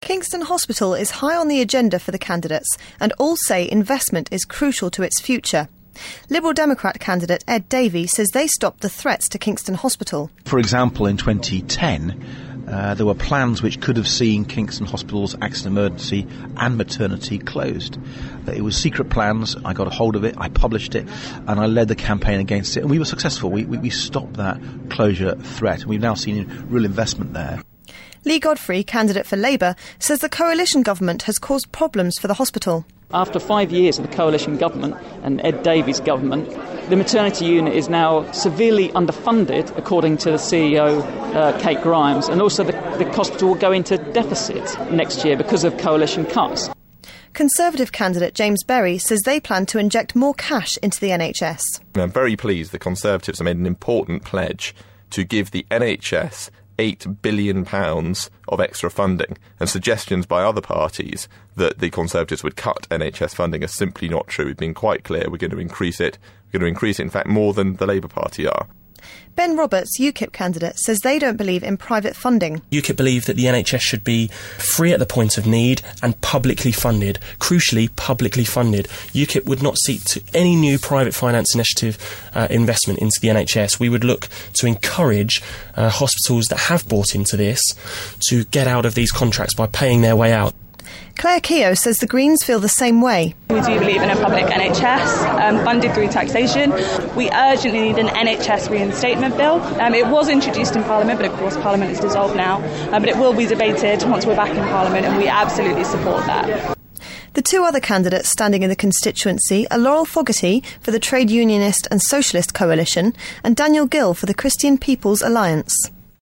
Election Report on the NHS